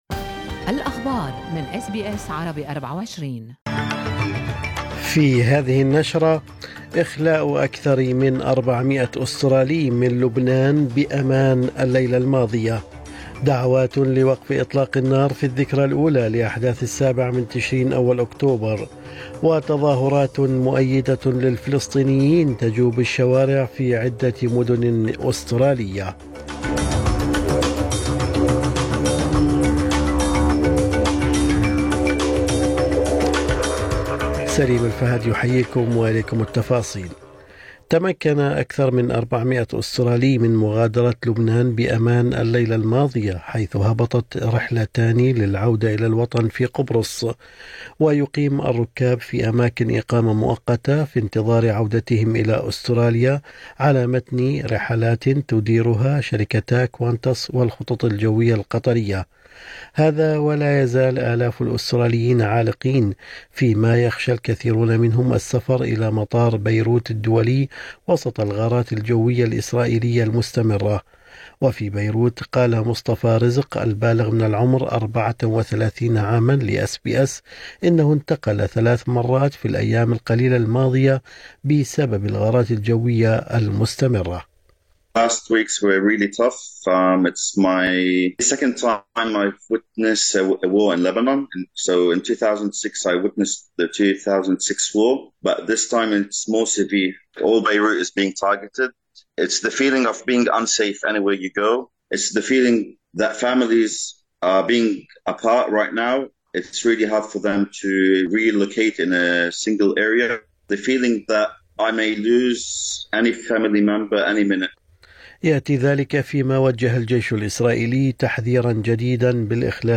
نشرة أخبار الصباح 7/10/2024